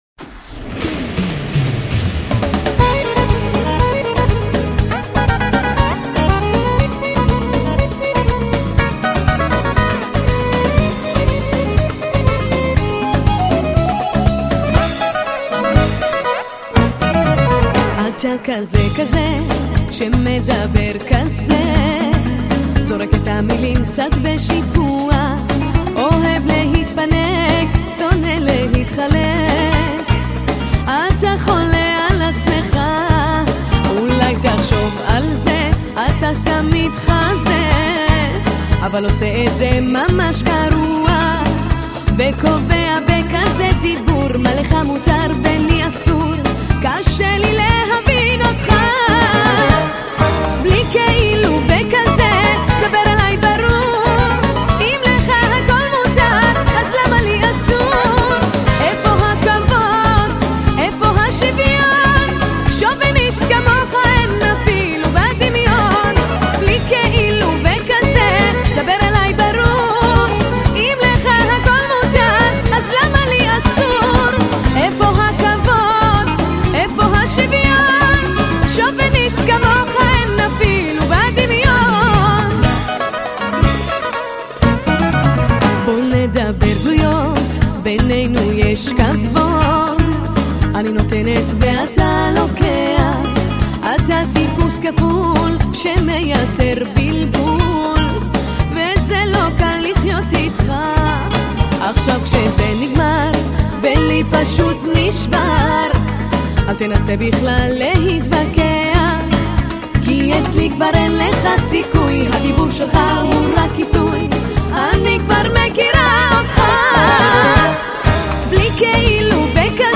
Пожалуйста, подскажите исполнительницу этой "залихватской" песенки.